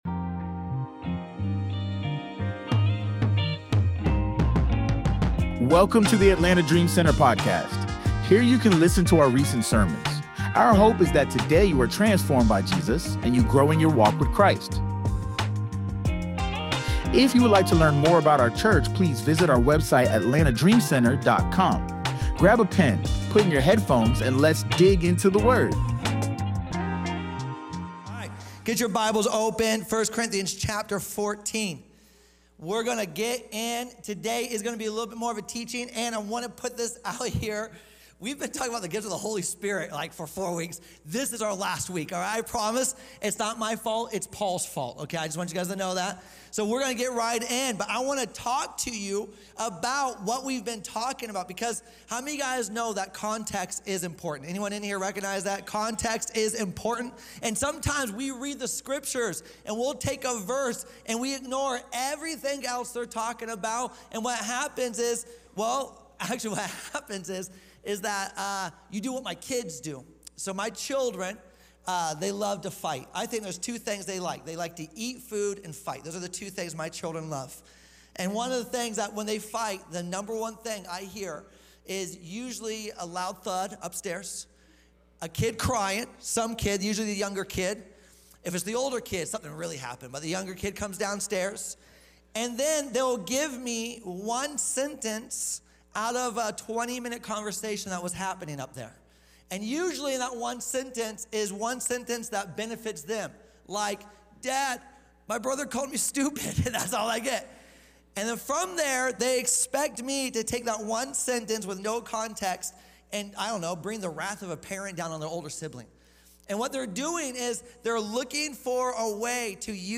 Sunday Livestream